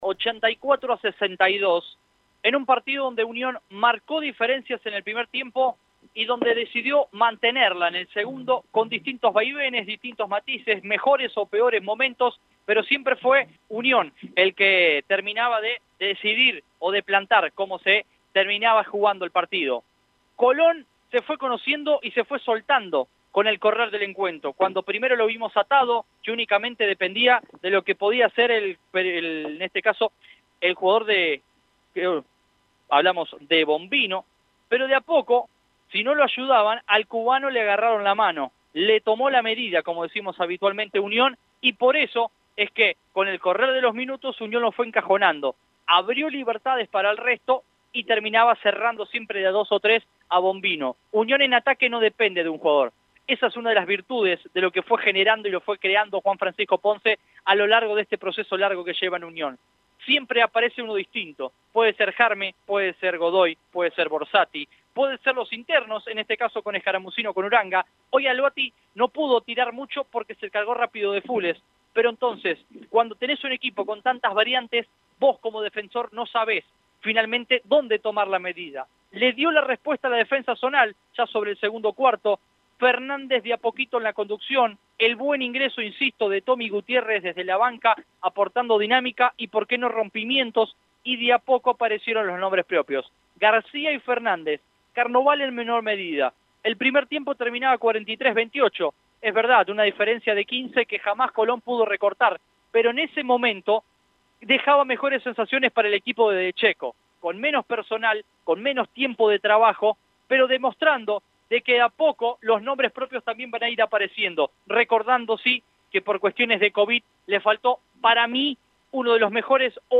COMENTARIO.mp3